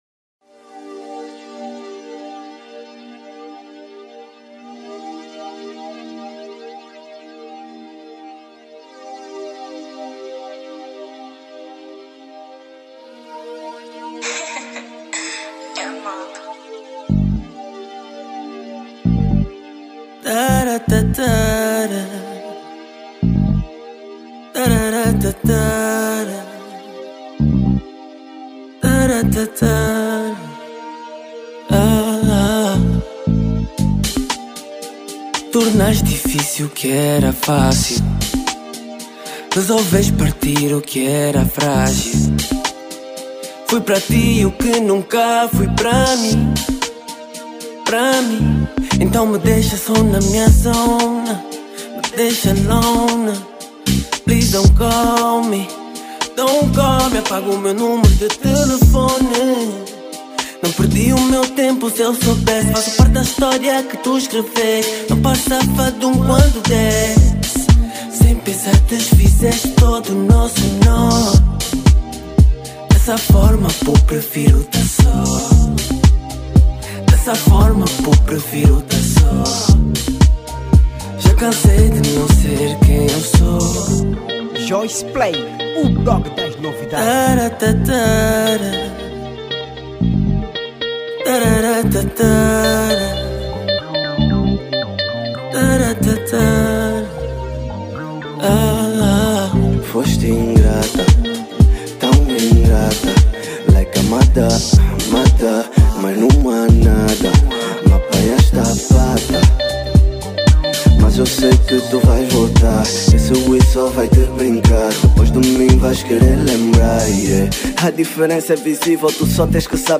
Género: Hip-Hop / Rap